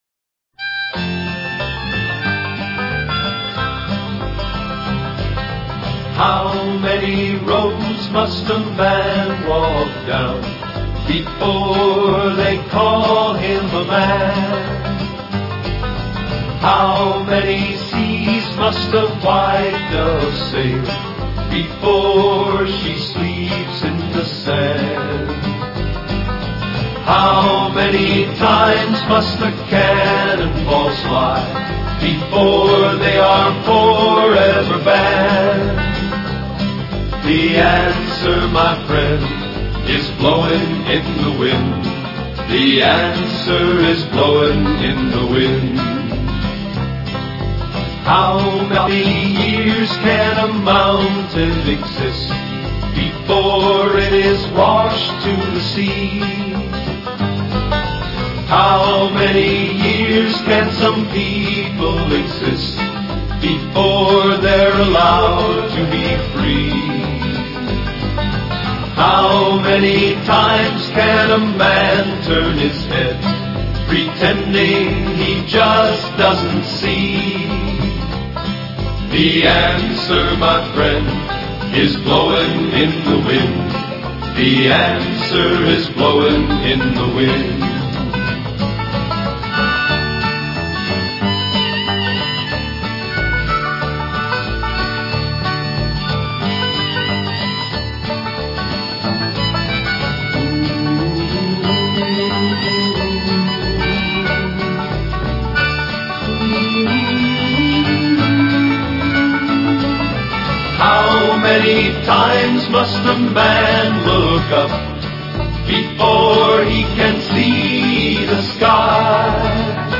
90年颁奖典礼上的英文medley